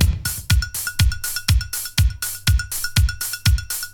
• 122 Bpm 80's Breakbeat Sample E Key.wav
Free breakbeat sample - kick tuned to the E note. Loudest frequency: 4405Hz